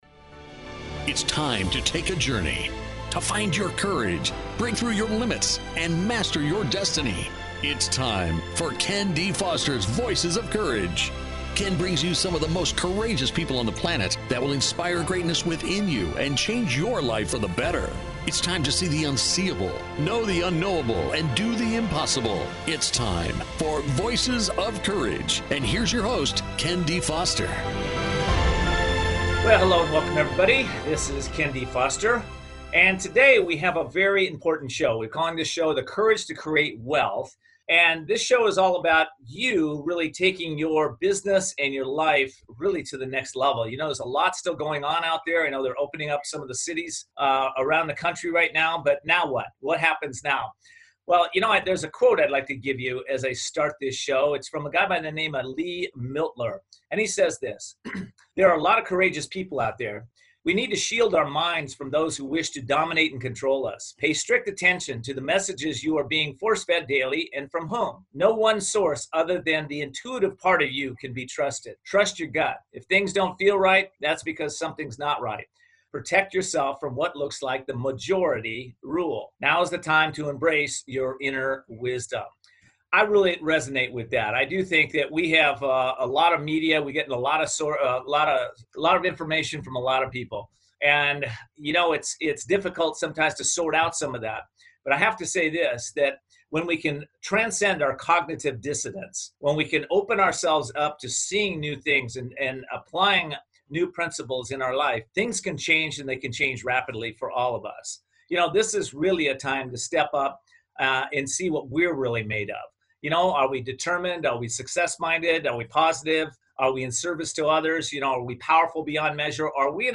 Guest, Robert Kiyosaki